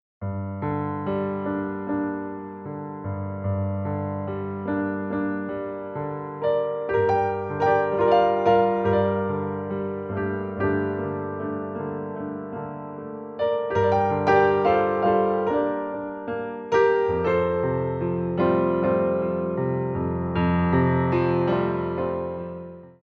Stretching at the Barre